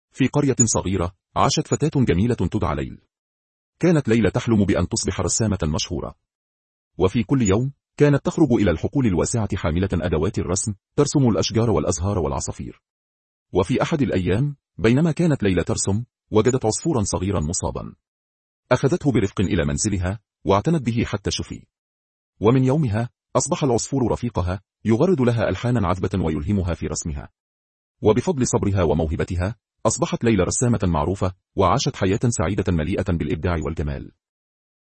أمثلة لنتائج مولد الأصوات المجاني المتقدم VocalAI
١. توليد صوت عربي سعودي